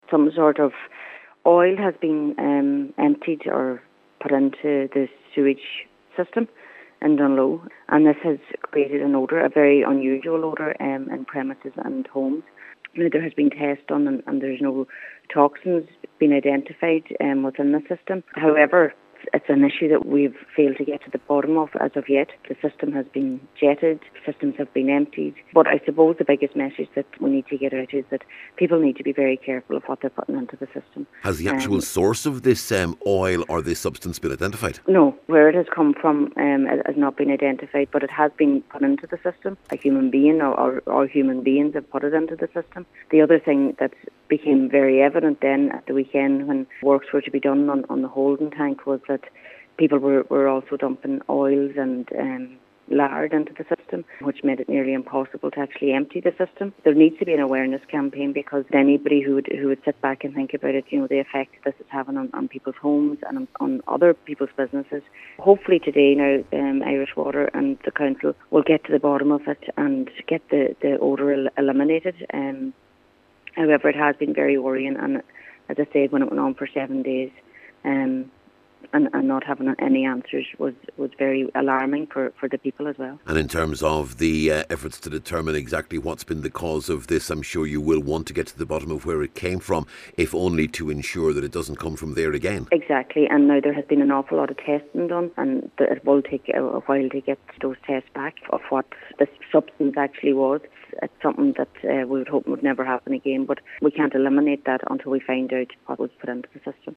No toxins have been identified, but Cllr Gallagher says it’s important that the source of the contamination is found…………